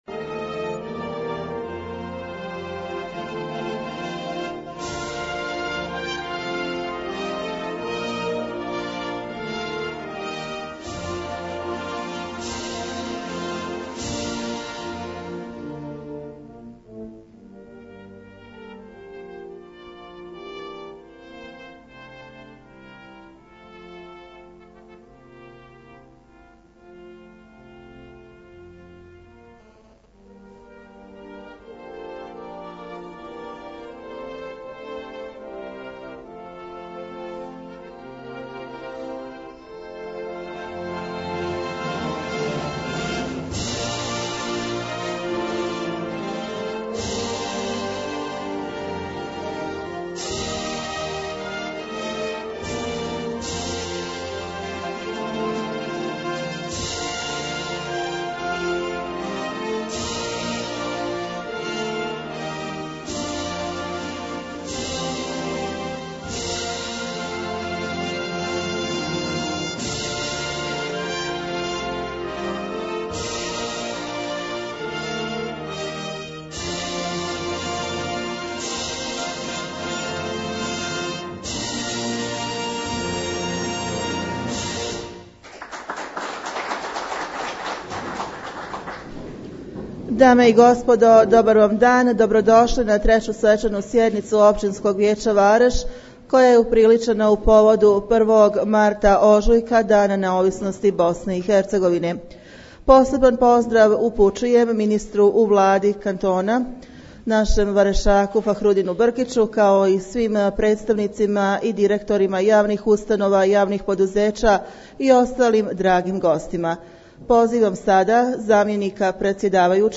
3. svečana i 15. redovna sjednica Općinskog vijeća
U srijedu 28. veljače 2018. godine održana je 3. svečana sjednica Općinskog vijeća povodom Dana neovisnosti Bosne i Hercegovine,